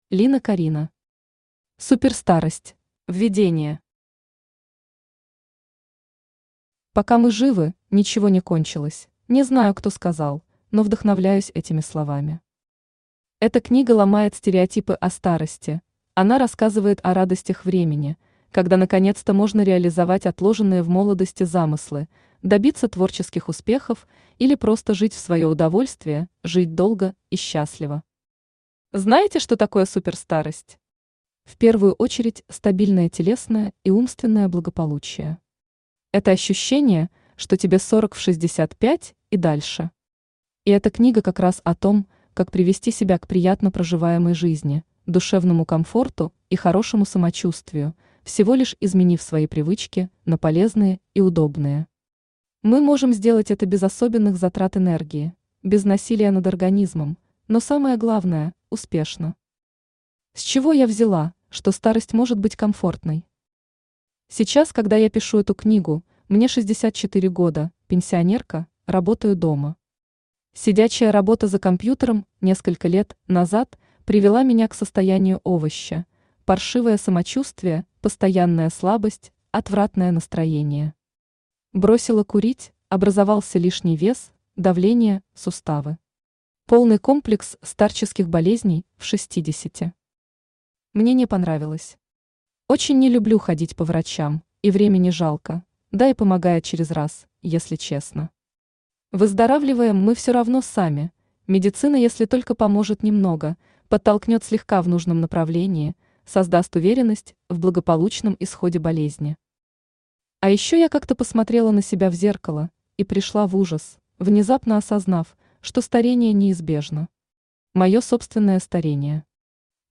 Aудиокнига Суперстарость Автор Лина Корина Читает аудиокнигу Авточтец ЛитРес.